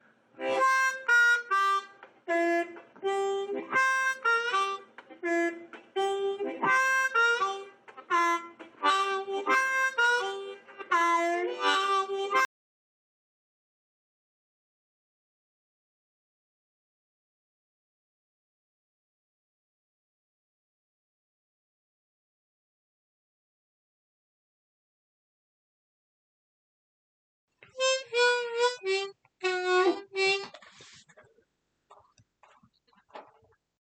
EDIT: Okay re-listening to it and to myself and experimenting a bit more, part of it seems to make the final note last longer, however the notes are still somewhat different - the example also used a C harmonica.